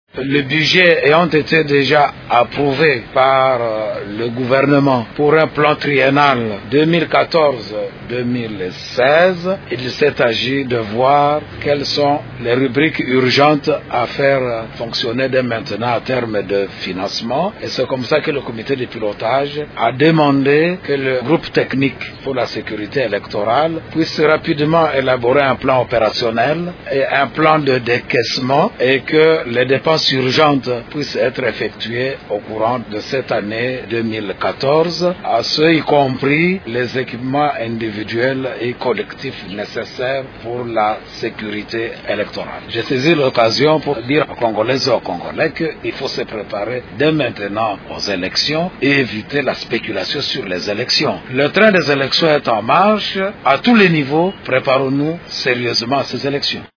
Au terme de cette réunion de vendredi, l’abbé Malumalu a lancé cet appel aux Congolais :